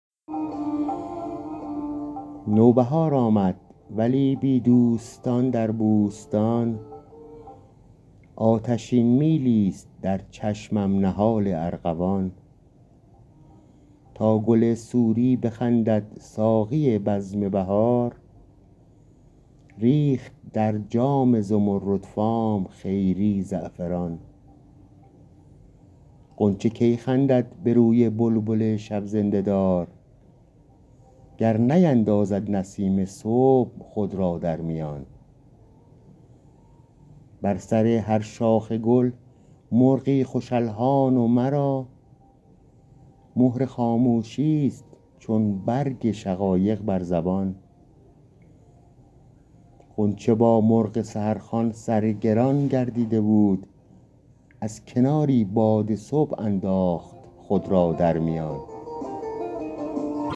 گنجور » نمایش خوانش